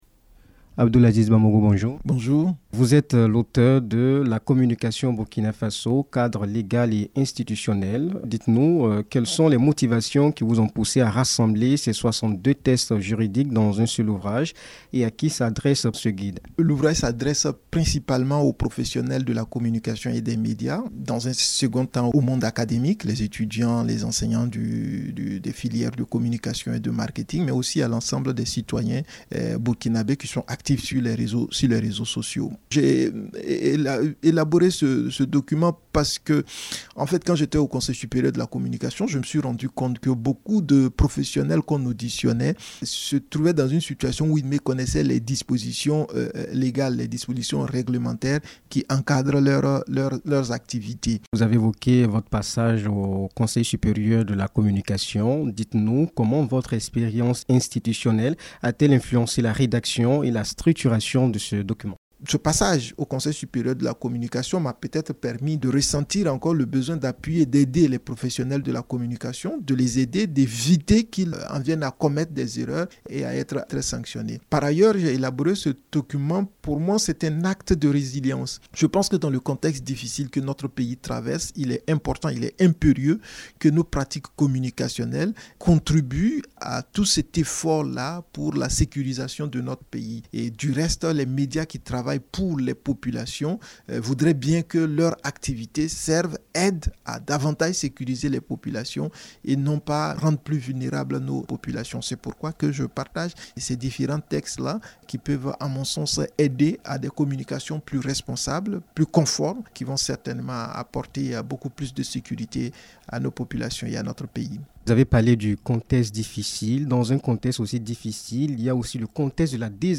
L’ancien président du Conseil supérieur de communication, Abdoulazize Bamogo est l’invité de la rédaction de ce 24 juillet 2025. Il évoque la question de son guide intitulé « La communication au Burkina Faso, cadre légal et institutionnel ».